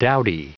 Prononciation du mot : dowdy